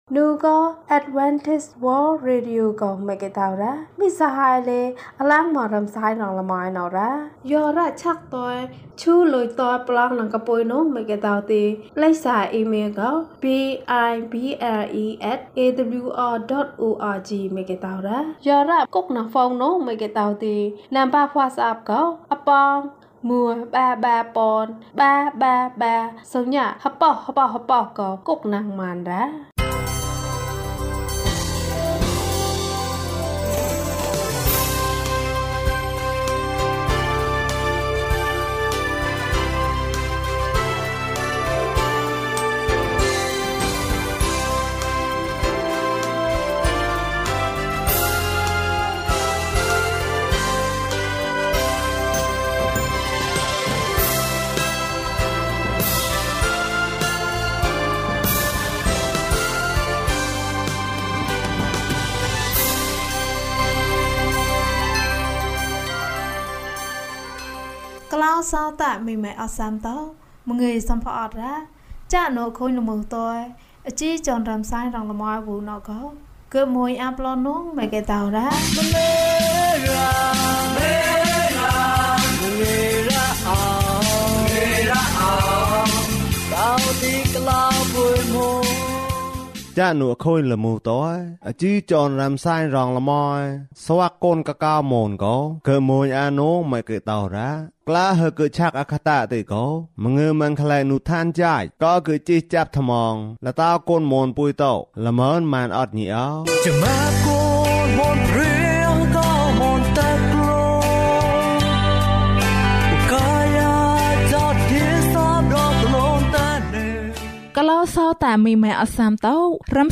ခရစ်တော်ထံသို့ ခြေလှမ်း။၃၂ ကျန်းမာခြင်းအကြောင်းအရာ။ ဓမ္မသီချင်း။ တရားဒေသနာ။